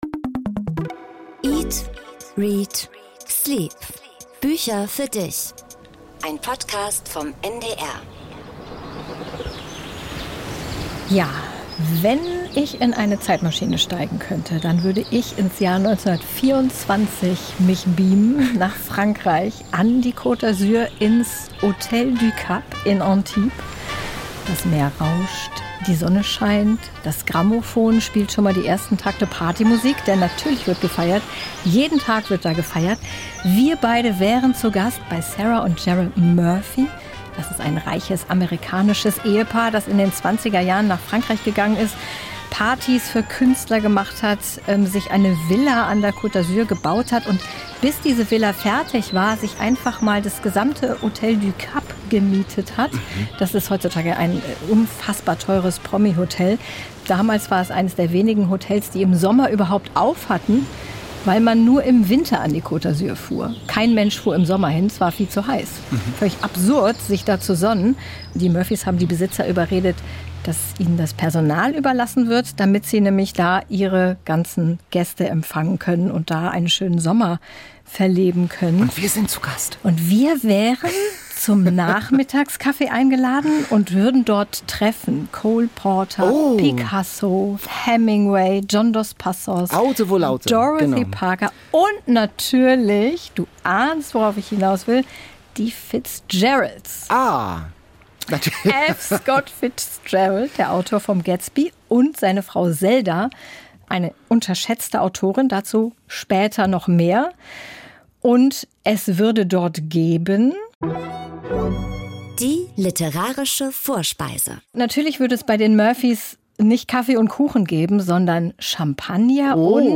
In Folge 34 rauscht das Meer, geht es um die Sehnsucht nach der Ferne.
00:25:09 Interview mit Mithu Sanyal